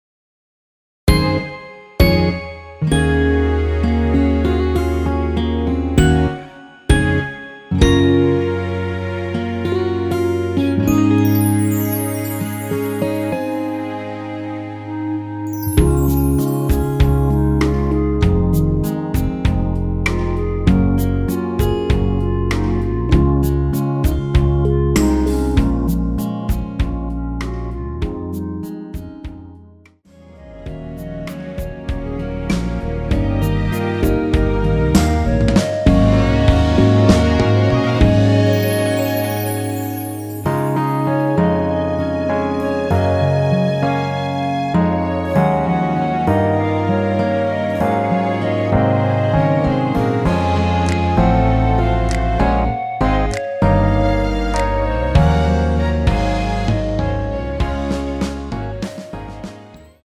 원키에서(-1)내린 1절후 후렴으로 진행되는 멜로디 포함된 MR입니다.
Db
앞부분30초, 뒷부분30초씩 편집해서 올려 드리고 있습니다.
중간에 음이 끈어지고 다시 나오는 이유는